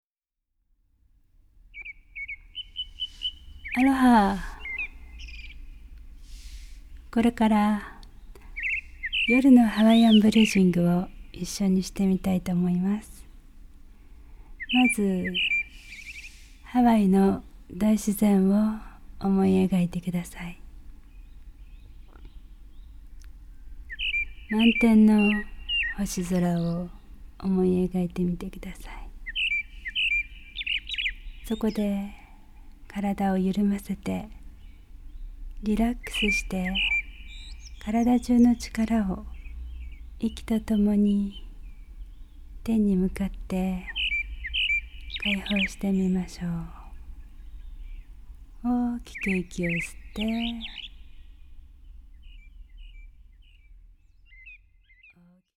夜のリラクゼーションの内容は、やはりハワイの小鳥たちをバックミュージックにして、呼吸の仕方などがナレーターされているものでした
ねむくなりそうな、とても心地のいい誘導です